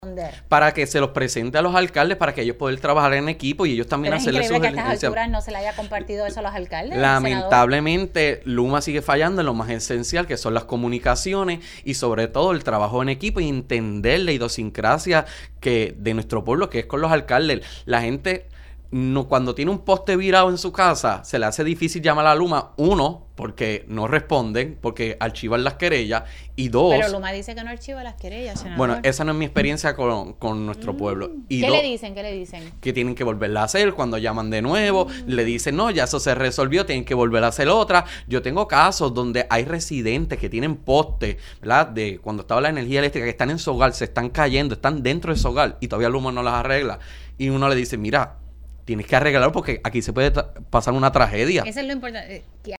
“[…] gracias a Dios Erin no tocó tan directamente, pero sí en la montaña hubo unos estragos donde se cayeron unas líneas, pero nuestros alcaldes no tenían comunicación con LUMA”, dijo el senador en Pega’os en la mañana.